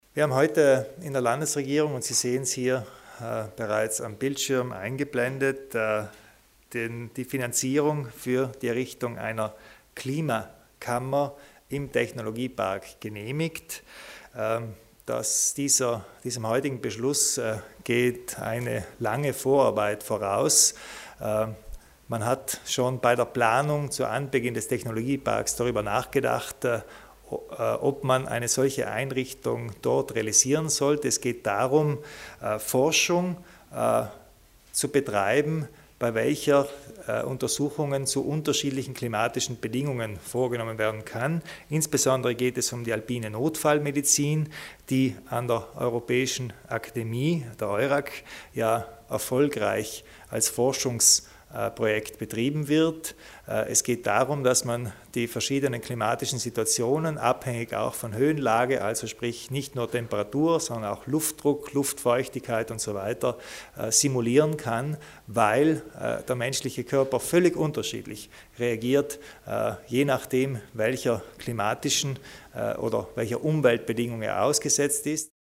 Landeshauptmann Kompatscher zum Projekt des Instituts für Alpine Notfallmedizin